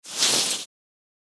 Download Free Foley Sound Effects | Gfx Sounds
Polyester-movement-scraping-6.mp3